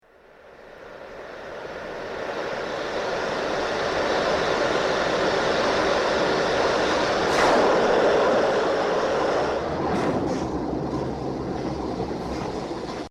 Hiss And Whoosh